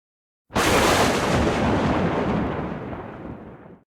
thunder1.ogg